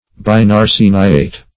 Search Result for " binarseniate" : The Collaborative International Dictionary of English v.0.48: Binarseniate \Bin`ar*se"ni*ate\, n. [Pref. bin- + arseniate.]
binarseniate.mp3